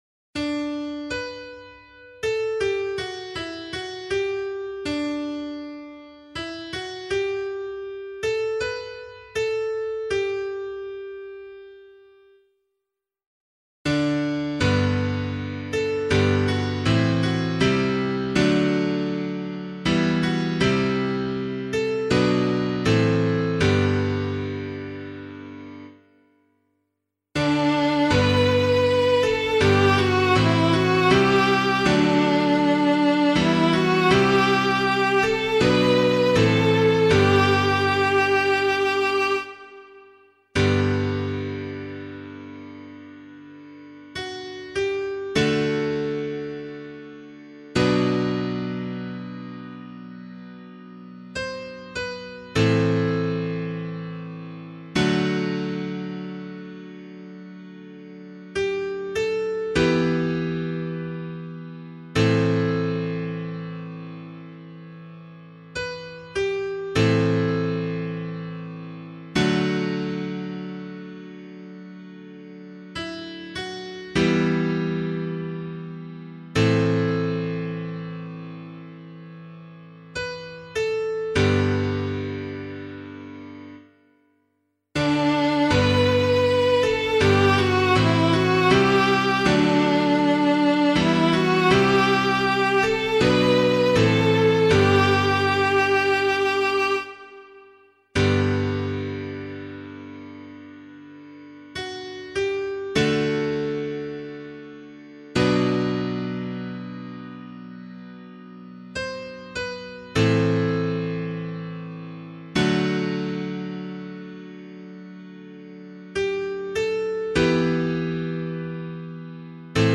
026 Easter 4 Psalm B [LiturgyShare 7 - Oz] - piano.mp3